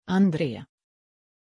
Pronunciación de Andrée
pronunciation-andrée-sv.mp3